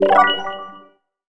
collect_item_02.wav